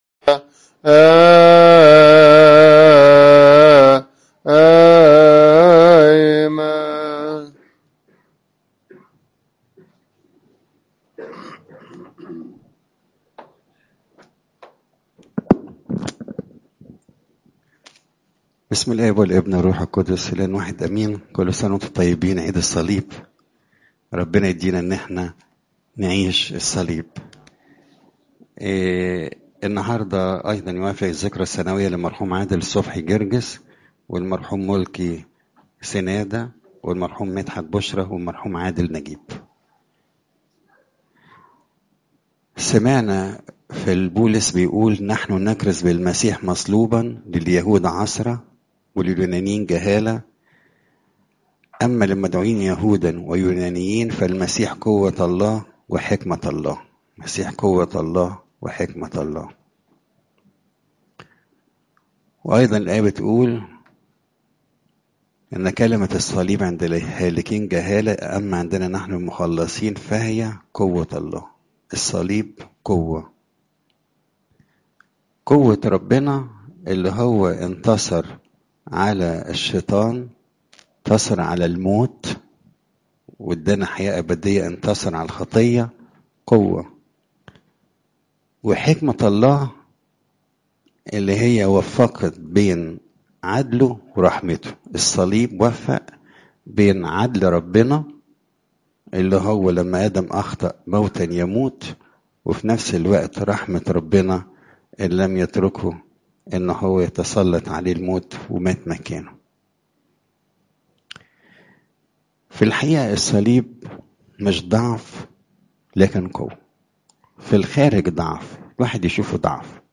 عظات المناسبات عيد الصليب (يو 10 : 22 - 38)